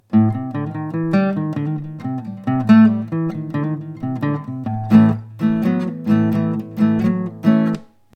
Acoustic Guitar Mic Example
One of the best tutorials I saw on micing had recordings of several different guitar mic positions.
Mics at 90 degrees halfway between the sound hole and the 12th fret.